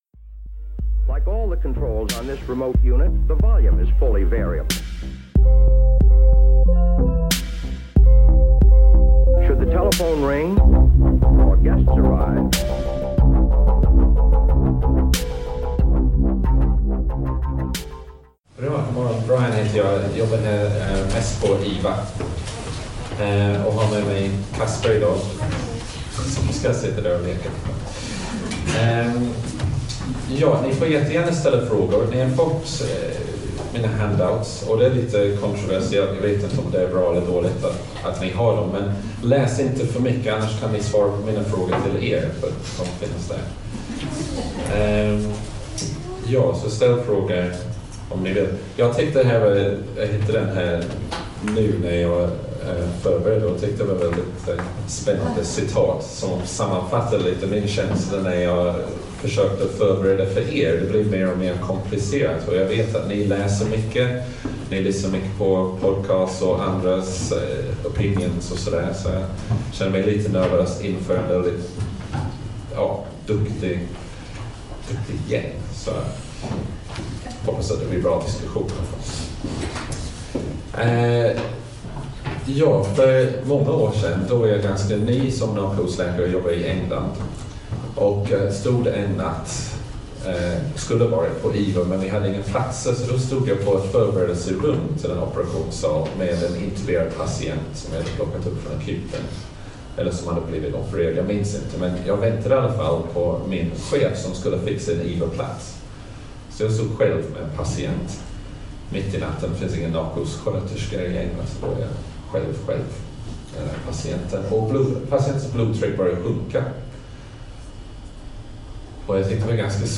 från ST-fredagen om hemodynamik och monitorering som hölls på SöS i 23 mars 2018.